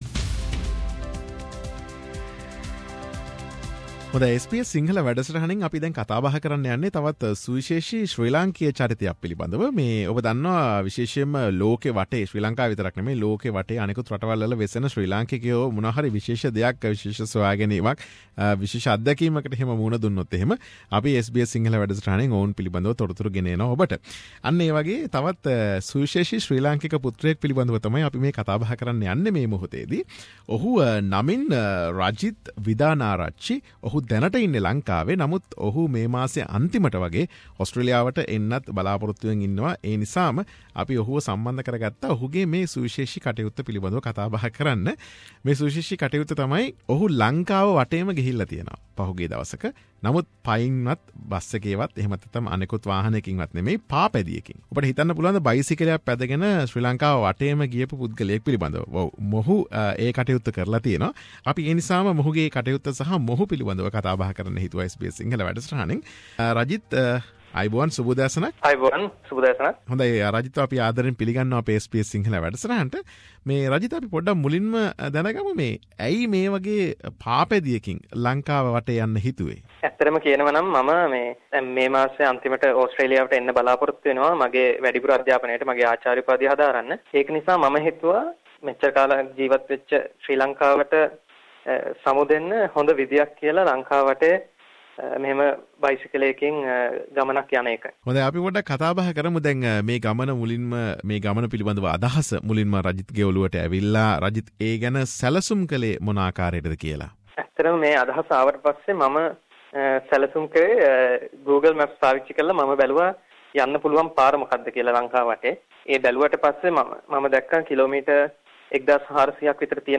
SBS සිංහල වැඩසටහන සිදු කළ පිළිසඳර.